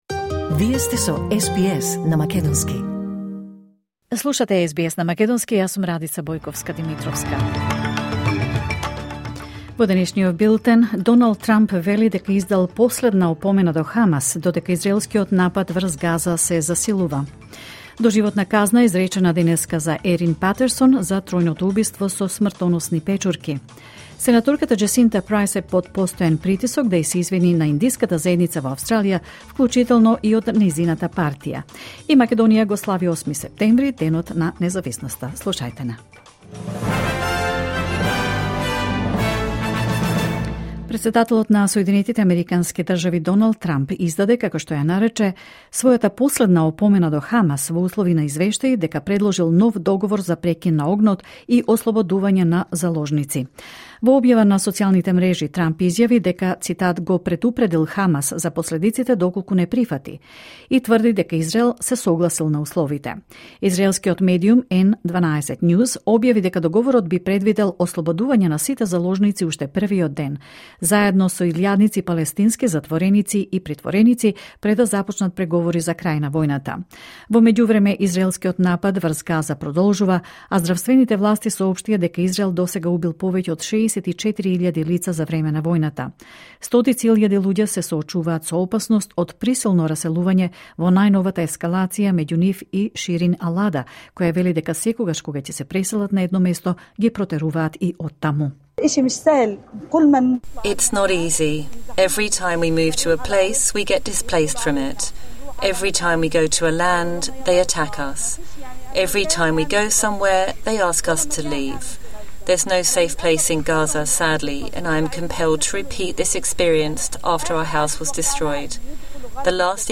Вести на СБС на македонски 8 септември 2025